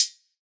Closed Hats
DDWV HAT 6.wav